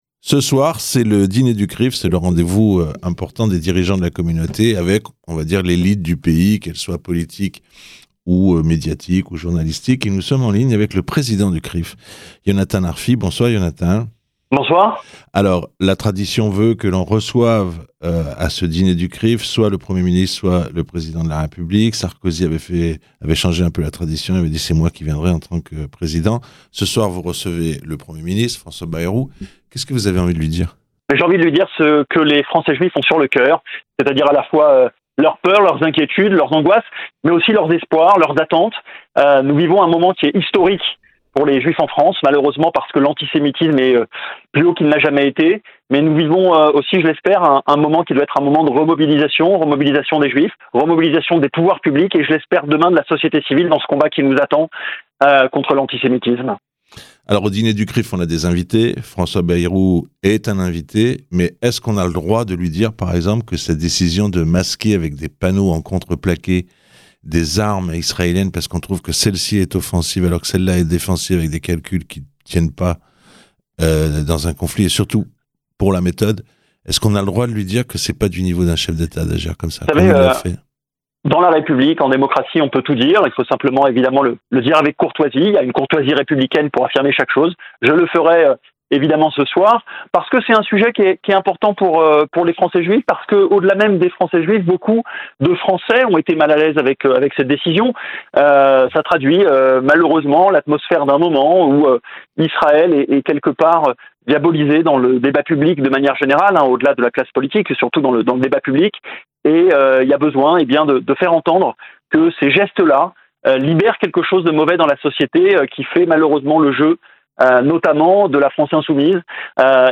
A l'occasion du dîner du CRIF